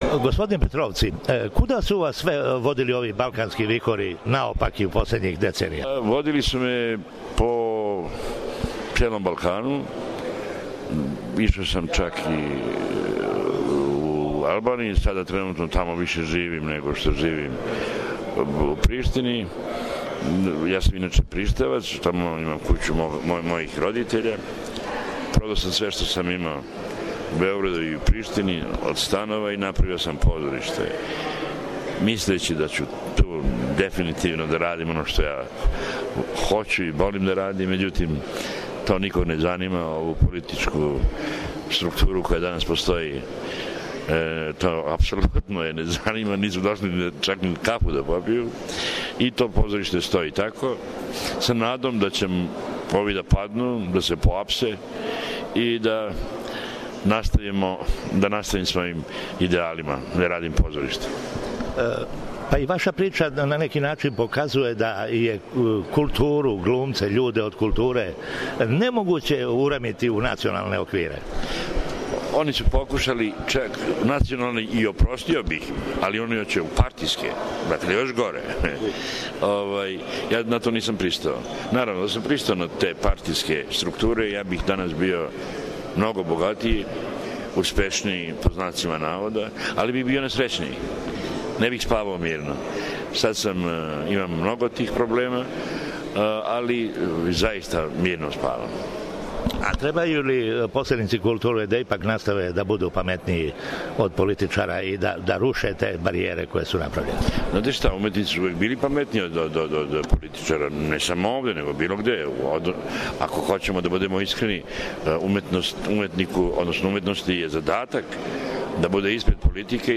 Интервју са Енвером Петровцијем